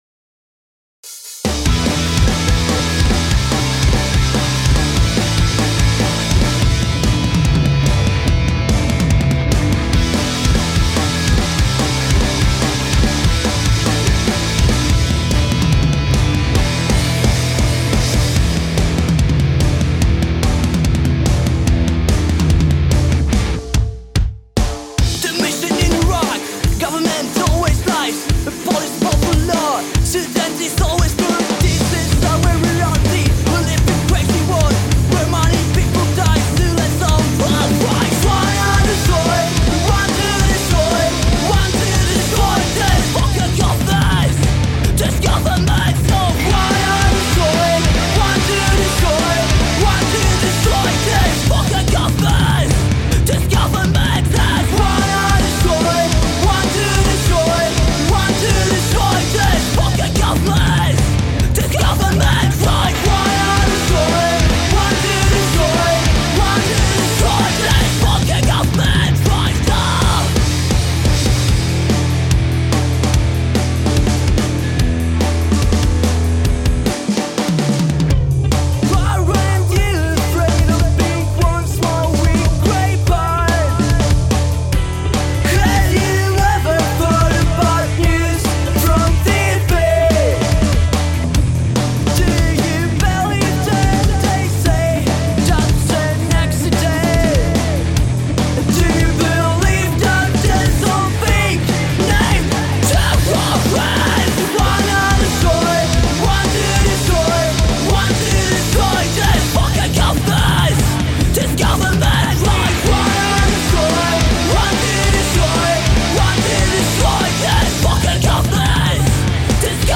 Gatunek: Punk / Hardcore
Gitara/Śpiew
Perkusja
Bass